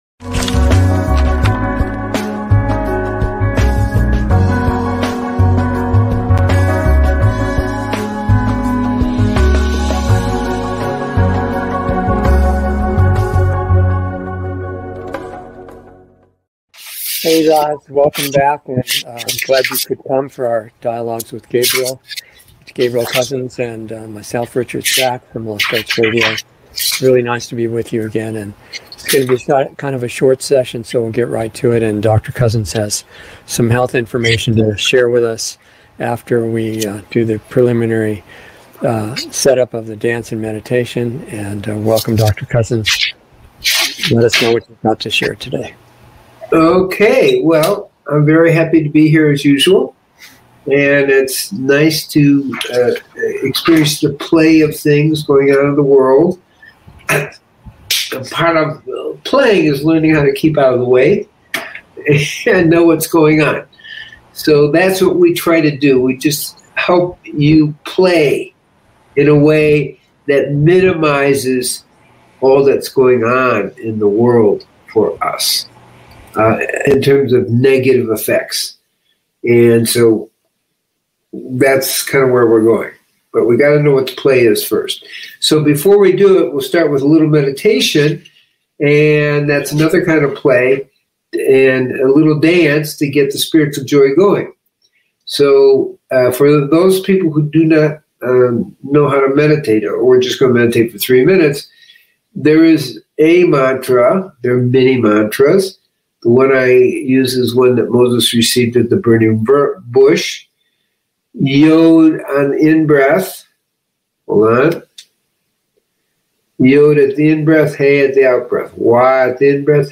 Meditation Grows Your Brain - Dialogs
A new LIVE series